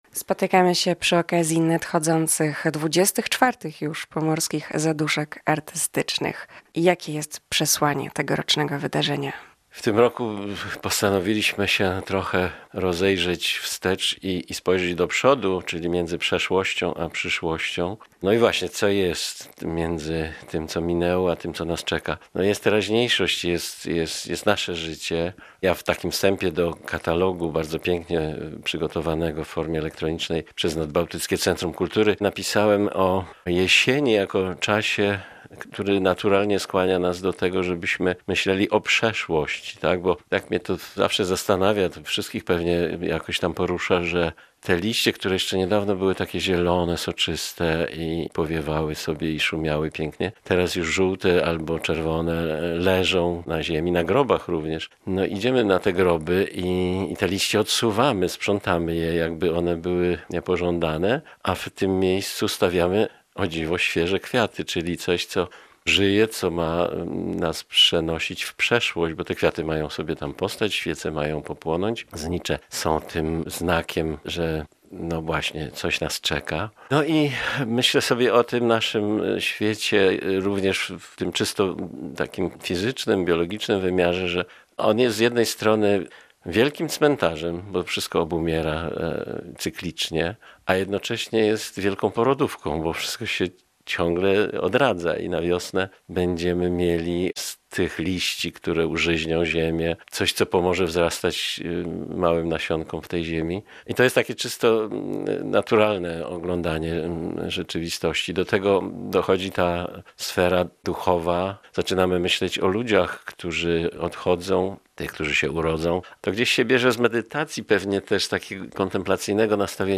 Gość Dnia Radia Gdańsk